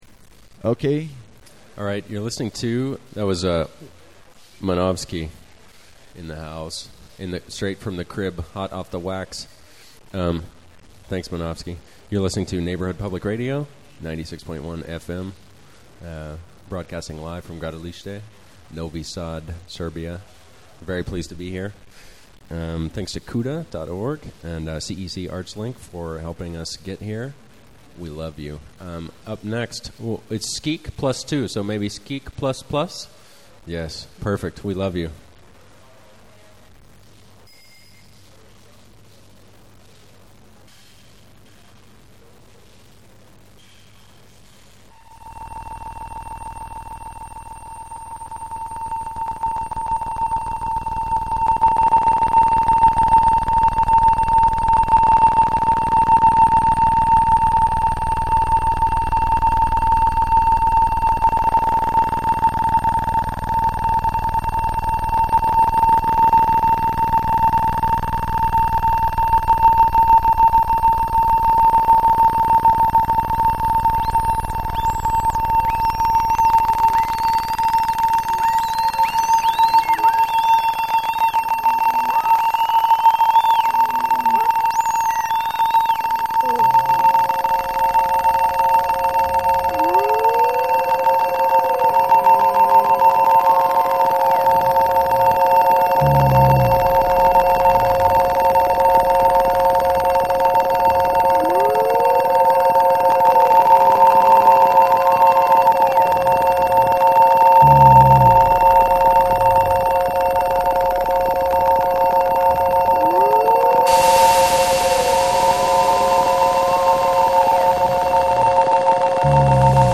mp3 je je neki lo-fi mono vbr ali sasvim pristojno zvuchi i ja sam uspeo realtime stream sa jako malo prekida zahvlajujuci uvdeneom ADSL prikljuchku.